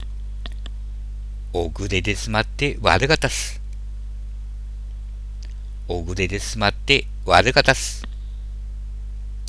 秋田の道端で交わされている挨拶です